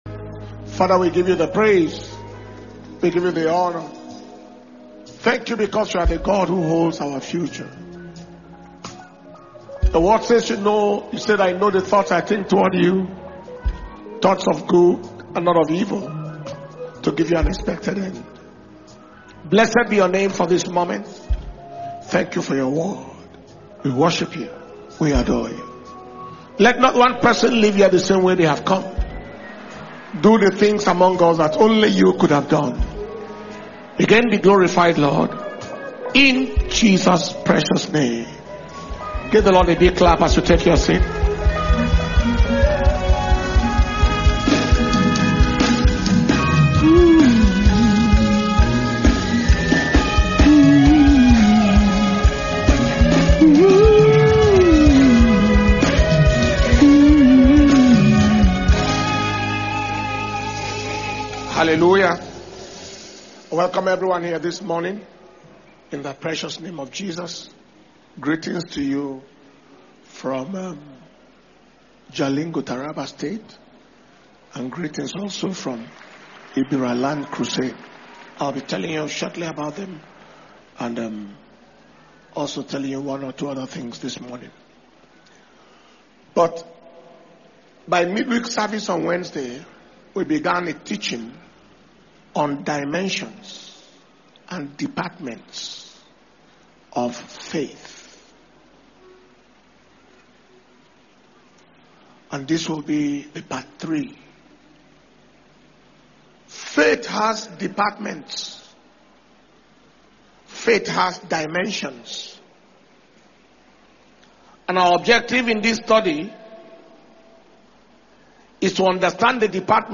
April 2026 Impartation Service – Sunday April 19th 2026